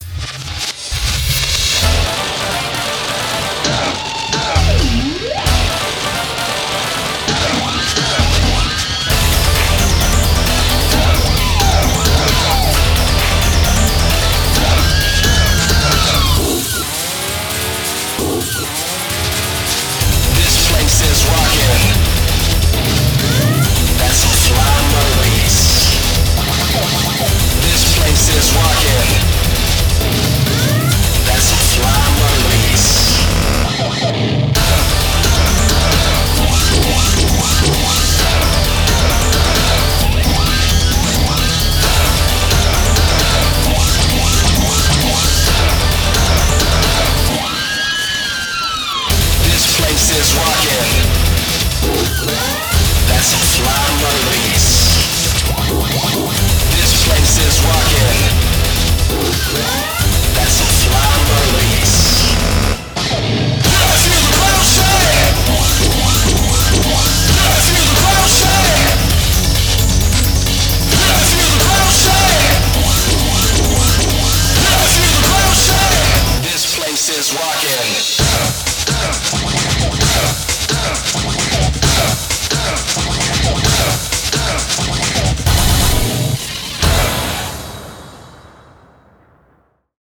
BPM132
Audio QualityPerfect (High Quality)
コメント[DIGITAL ROCK]